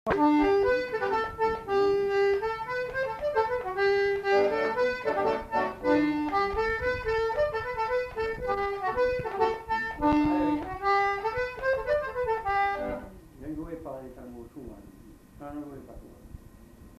Répertoire d'airs à danser du Marmandais à l'accordéon diatonique
enquêtes sonores